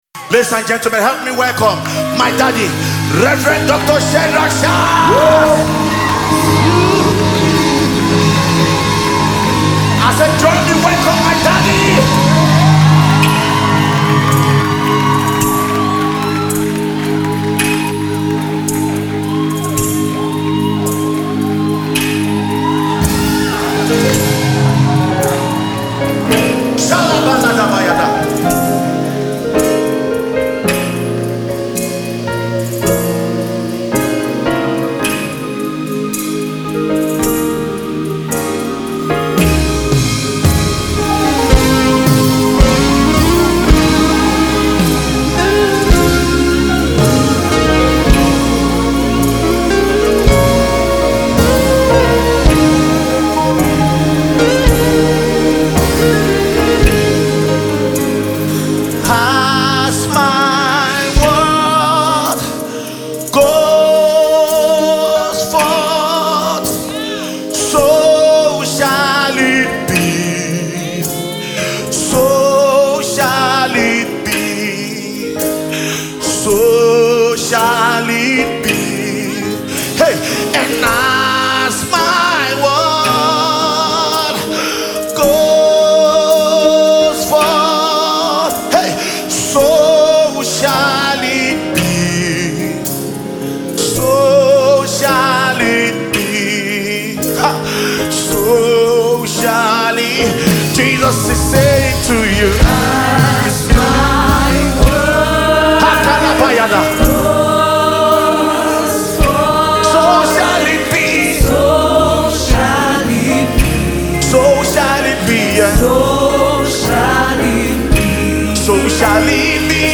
live recording song